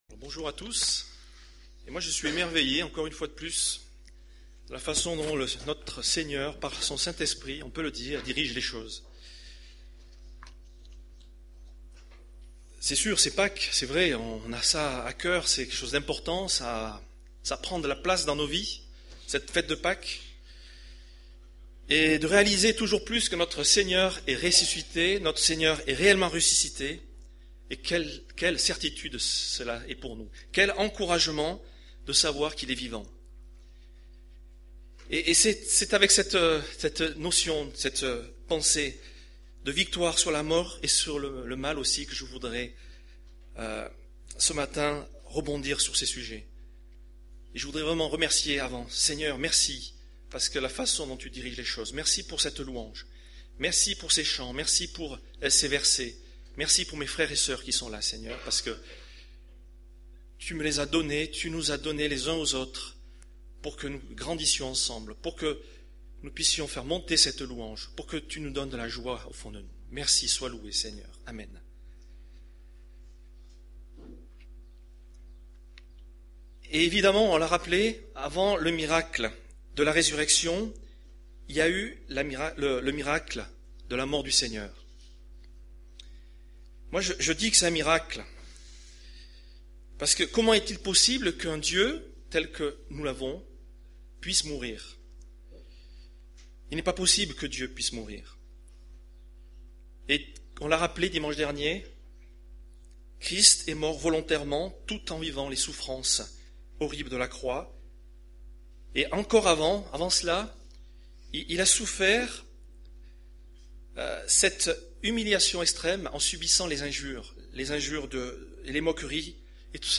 Culte du 15 avril